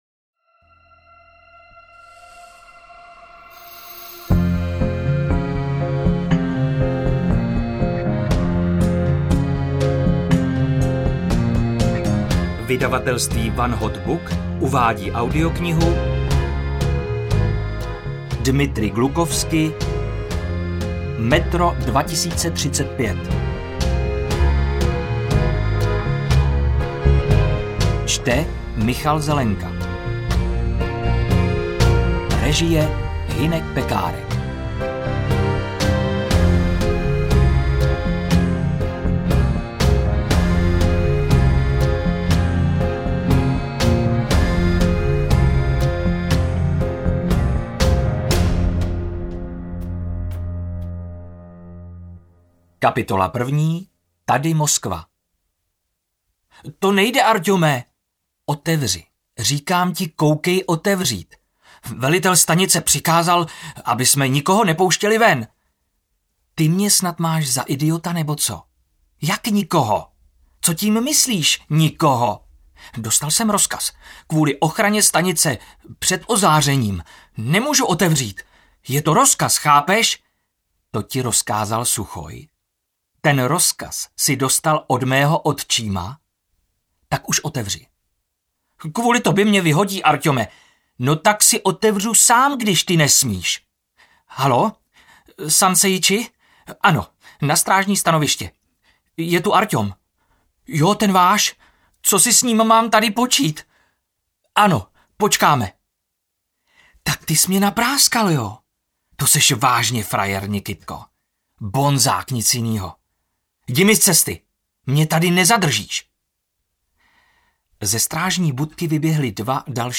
AudioKniha ke stažení, 58 x mp3, délka 19 hod. 48 min., velikost 1068,0 MB, česky